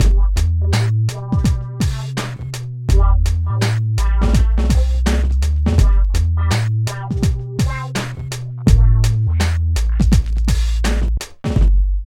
44 LOOP   -R.wav